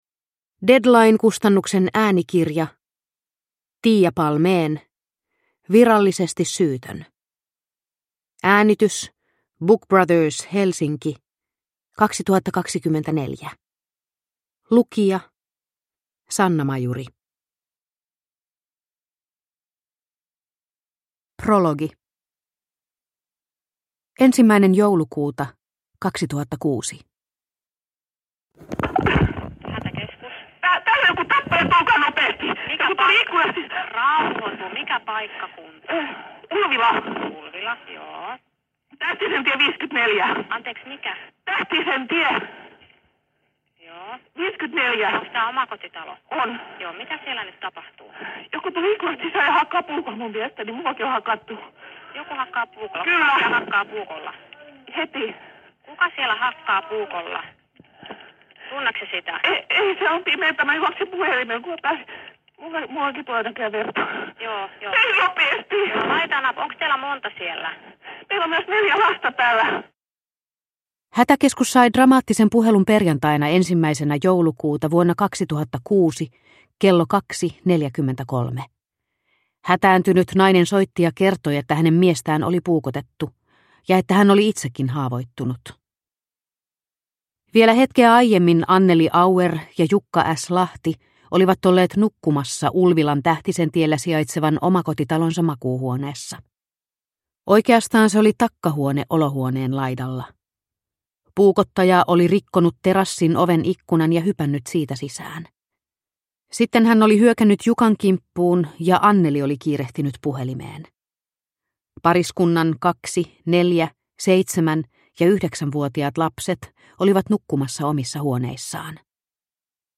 Virallisesti syytön – Ljudbok